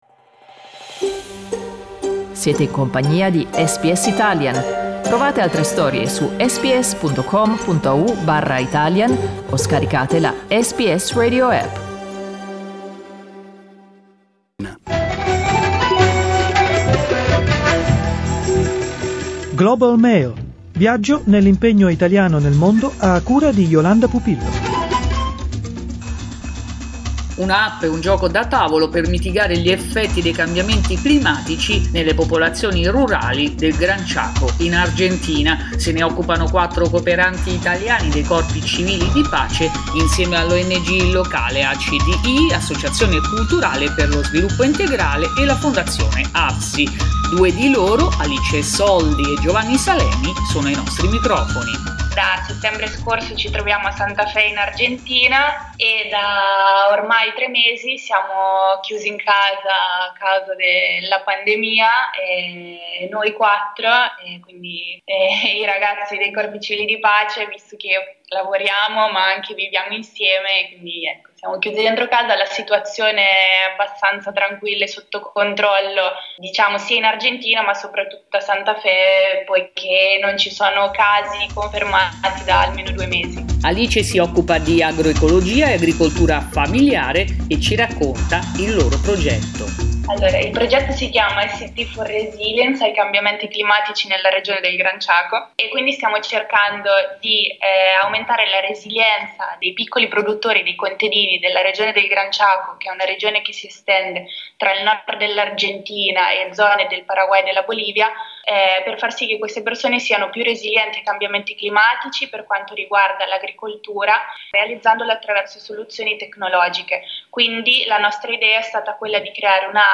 Listen to the interview in Italian